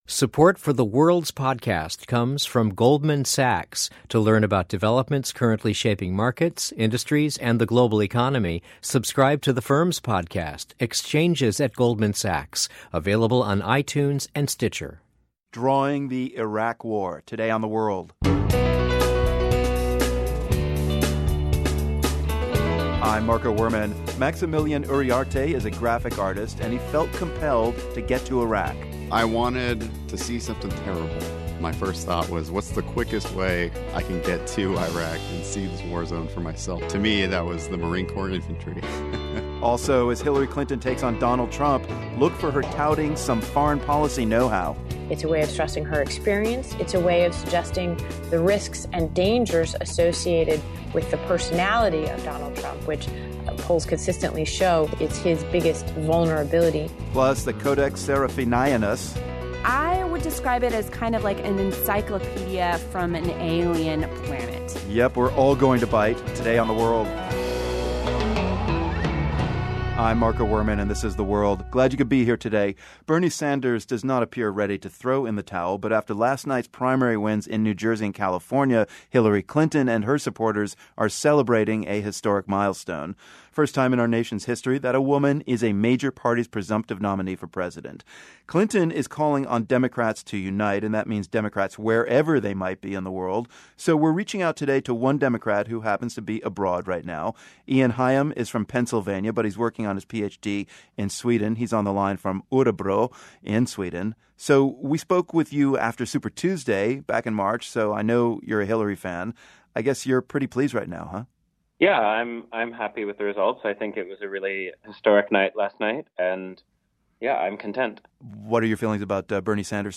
A view from overseas on Hillary Clinton's new status as the presumptive Democratic presidential nominee: We hear from a Clinton supporter in Sweden. Also, a conversation with a Marine who fought and drew his way through two tours of duty in Iraq.